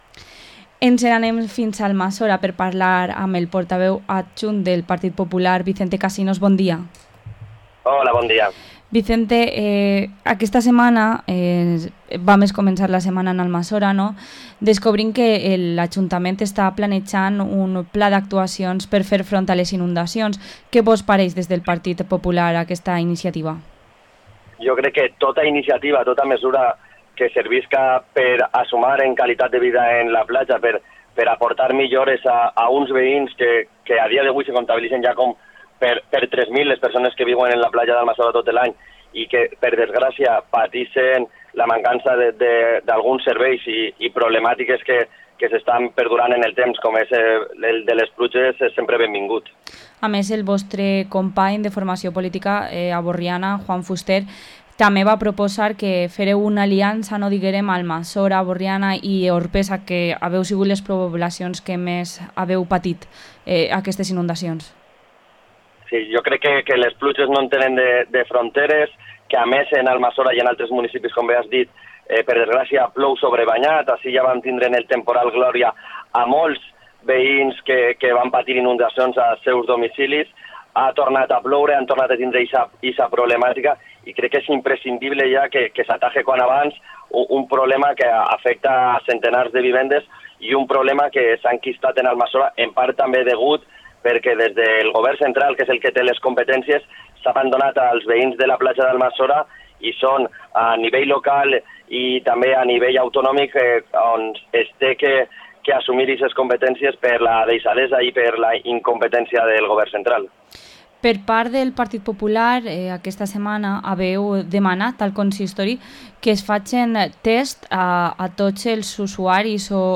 Entrevista al portavoz adjunto del Partido Popular en Almazora, Vicente Casinos